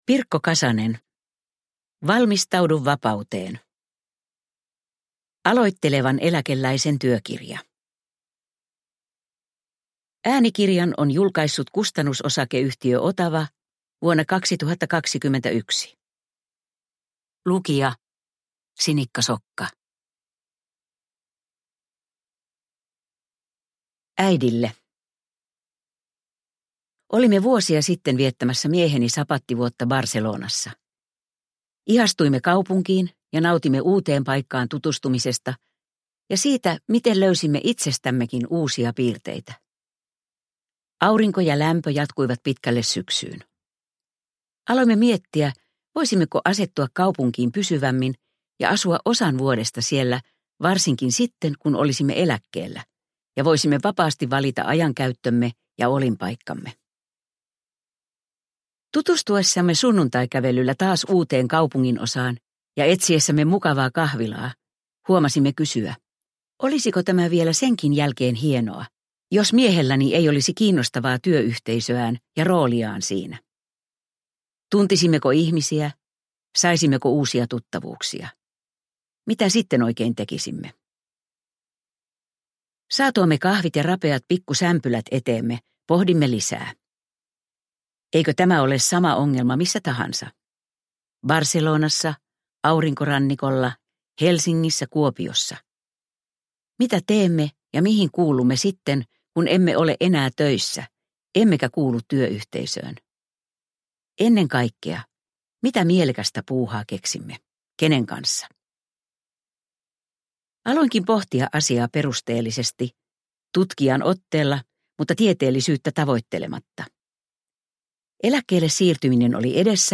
Valmistaudu vapauteen – Ljudbok – Laddas ner